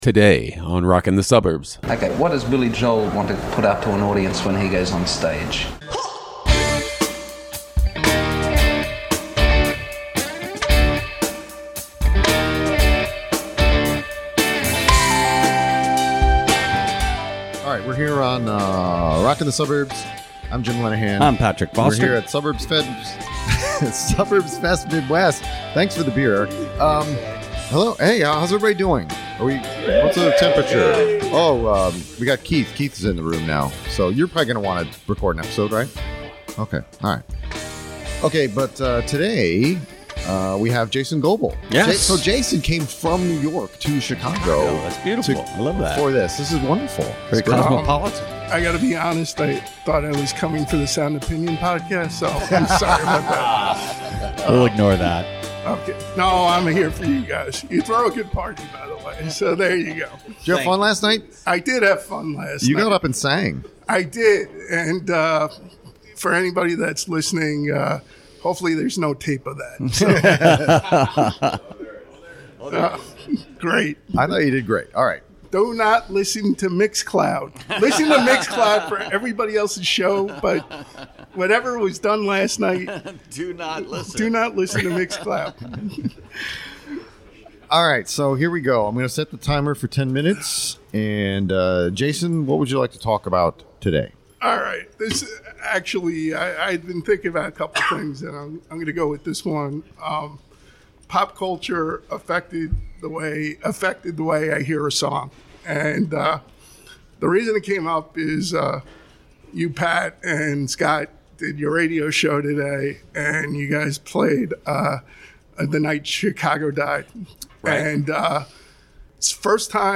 It's the seventh of the recordings we made on Day Two of Suburbs Fest Midwest! A crowd gathered at Arlington Ale House to join us and contribute.